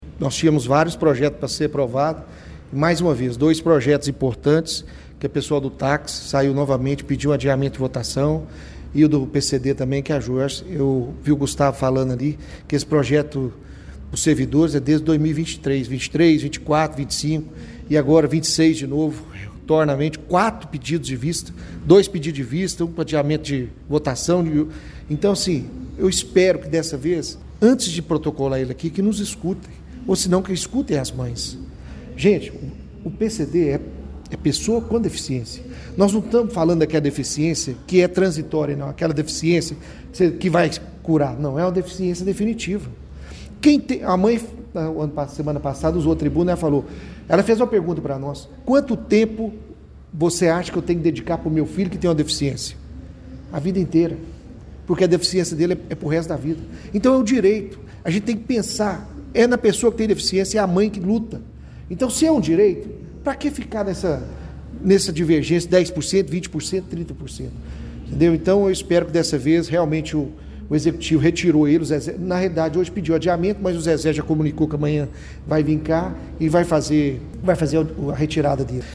O presidente do Legislativo, Geraldo Magela de Almeida, o Geraldinho Cuíca, informou que tem mantido tratativas diretas com o prefeito e com as secretarias de Gestão Pública e de Assistência e Desenvolvimento Social para ajustar o texto.